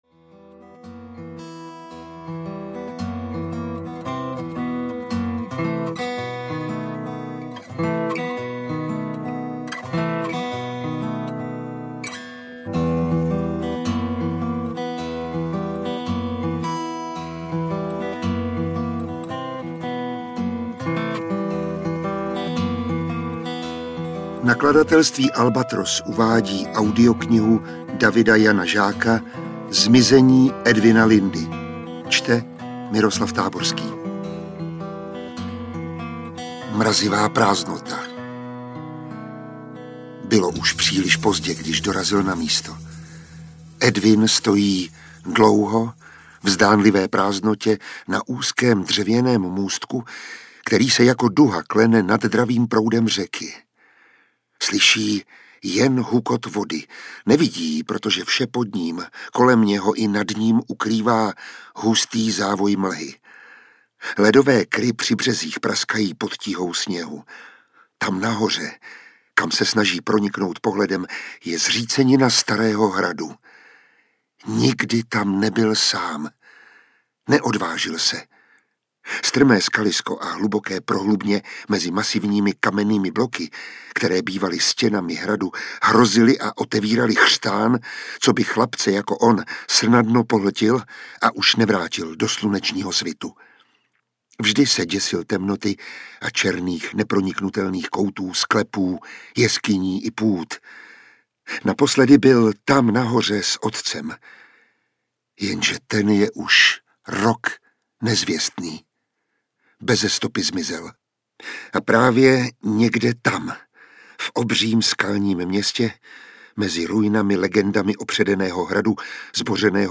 Zmizení Edwina Lindy audiokniha
Ukázka z knihy
• InterpretMiroslav Táborský